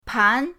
pan2.mp3